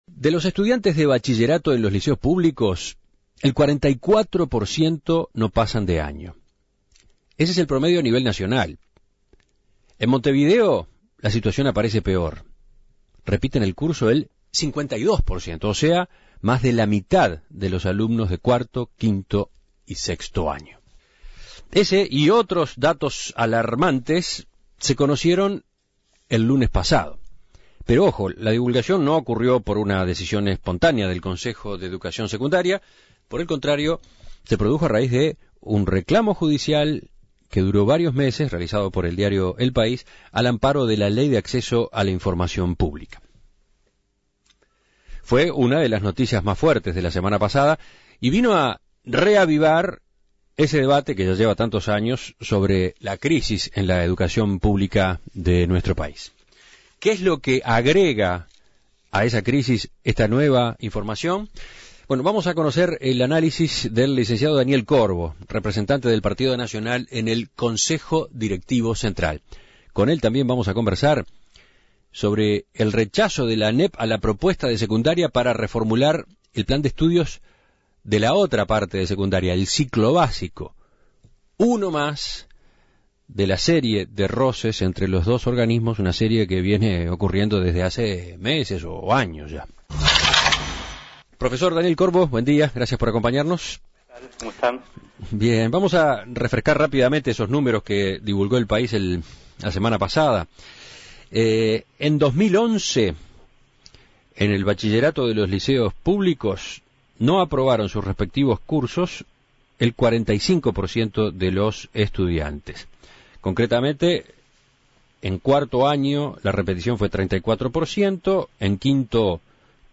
Un 44% de los alumnos que cursó el bachillerato en liceo público durante 2012 no aprobó el año, según datos del Consejo de Educación Secundaria conocidos la semana pasada. El representante del Partido Nacional en el Consejo Directivo Central (Codicen), Daniel Corbo, dijo a En Perspectiva que estas cifras son consecuencia de una crisis "estructural" de la educación media que se acentúa año a año.
Entrevistas